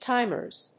/ˈtaɪmɝz(米国英語), ˈtaɪmɜ:z(英国英語)/
timers.mp3